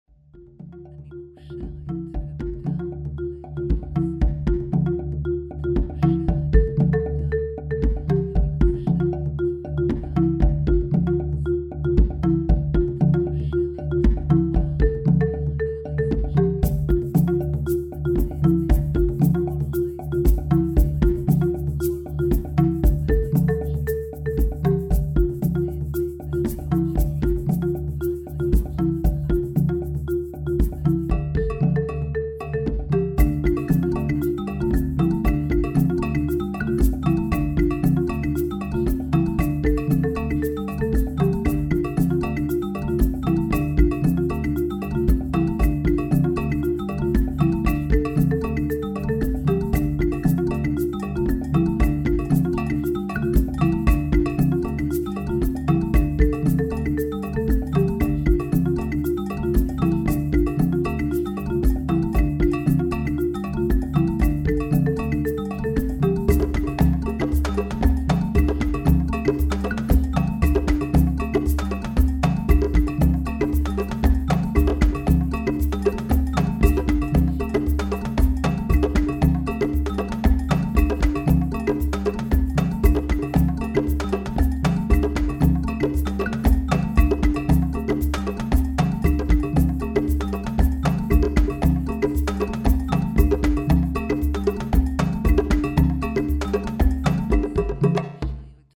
• בזמן ההקשבה תשמעו רק מוזיקה או קולות טבע.
דוגמה מהצהרות הסמויות עם מוזיקה להתעמלות וריצה להריון נוח ולידה קלה: